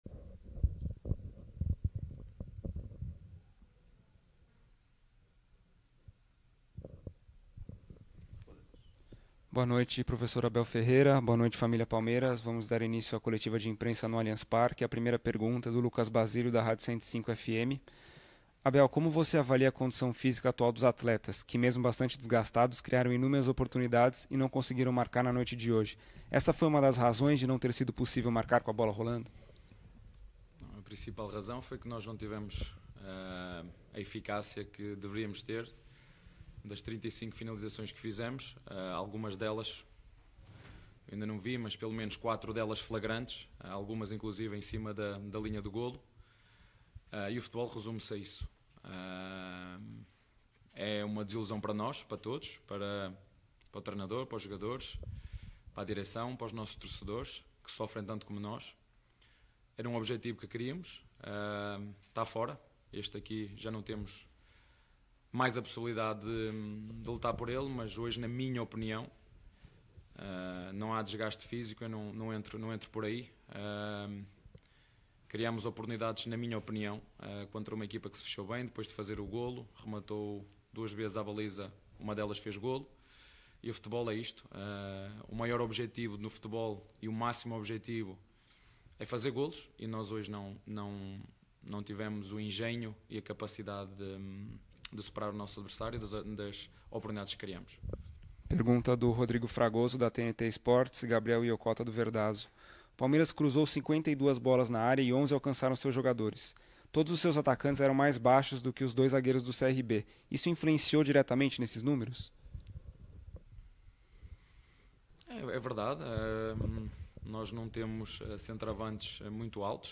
COLETIVA-ABEL-FERREIRA-_-PALMEIRAS-X-CRB.mp3